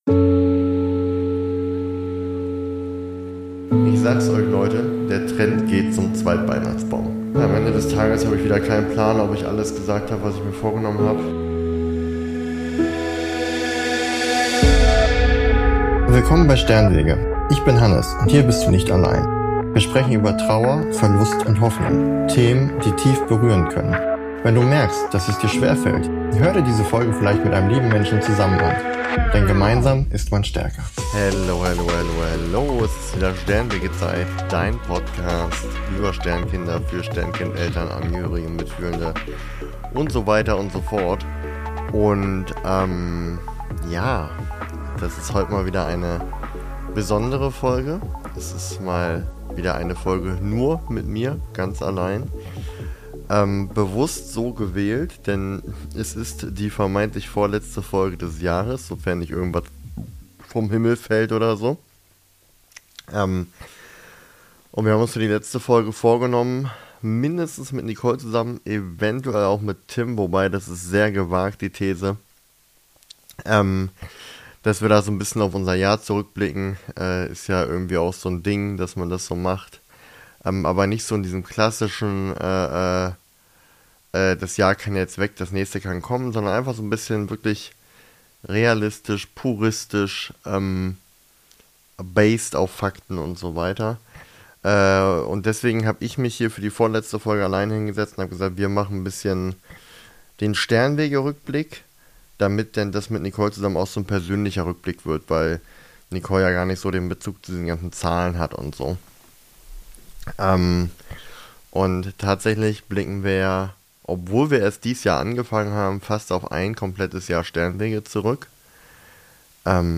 In dieser besonderen Solo-Folge blicke ich auf fast ein ganzes Jahr Sternenwege zurück.
Eine ruhige, persönliche Folge zum Jahresende – für alle, die diesen Weg mitgehen.